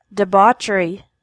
Listen and repeat to practice pronunciation of these funny-sounding English words.